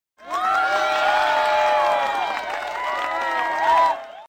roulette_settlementwin.mp3